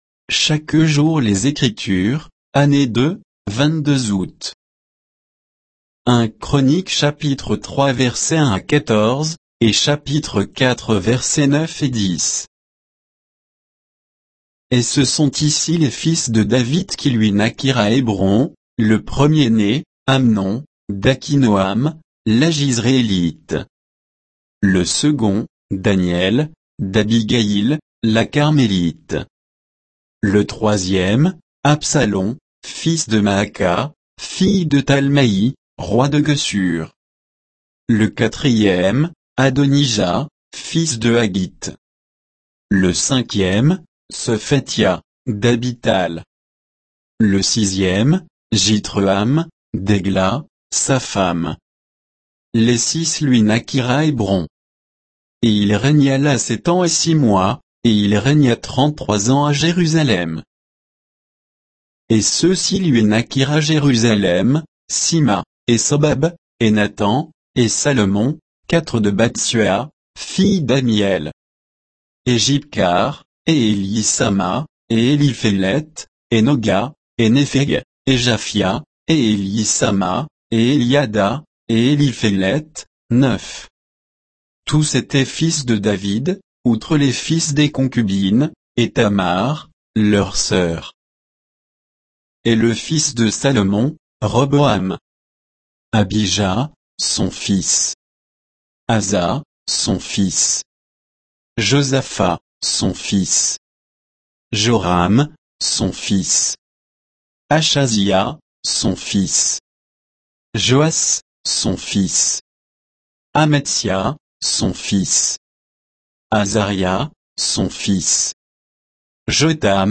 Méditation quoditienne de Chaque jour les Écritures sur 1 Chroniques 3, 1 à 14; 4, 9 et 10